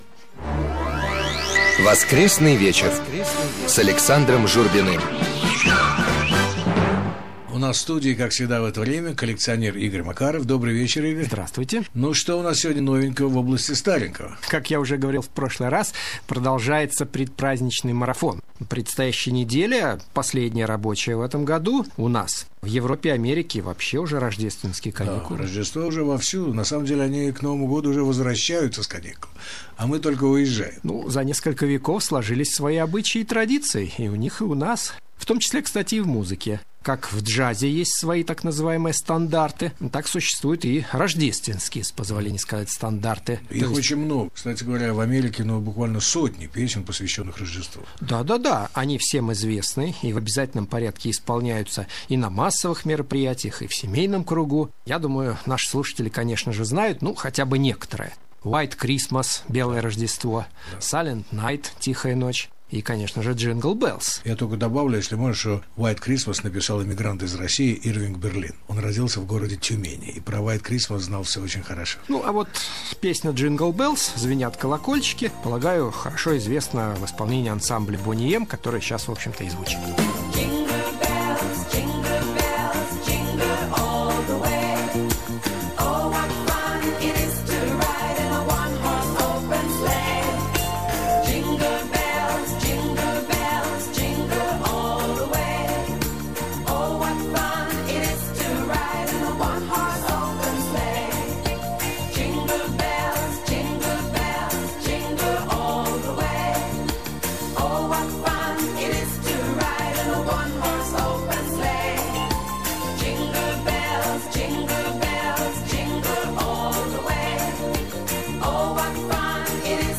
Музыкальные радиопередачи